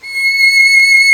Index of /90_sSampleCDs/Roland - String Master Series/STR_Violin 1-3vb/STR_Vln3 % marc
STR VLN3 C#6.wav